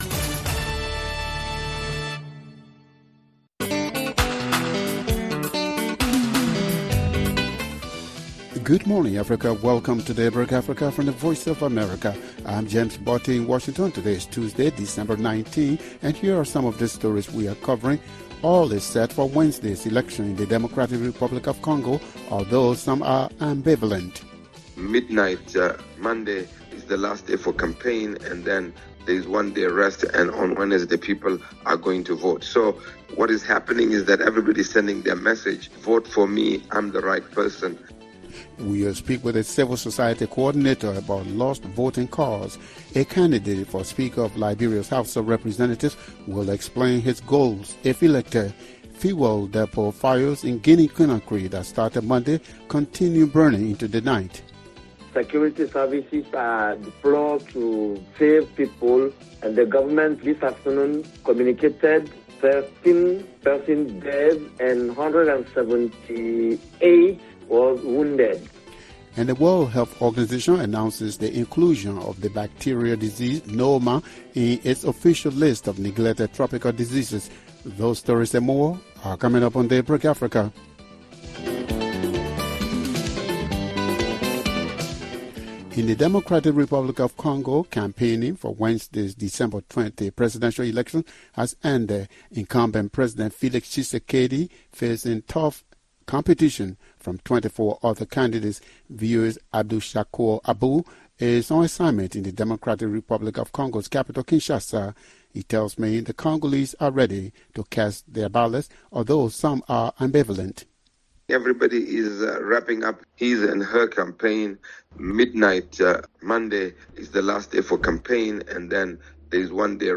On Daybreak Africa: Fuel depot fires in Guinea Conakry that started Monday continued burning into the night. Plus, all is set for Wednesday’s elections in the Democratic Republic of Congo, although some are ambivalent. We’ll speak with a civil society coordinator about lost voting cards. A candidate for speaker of Liberia’s House of Representatives will explain his goals if elected.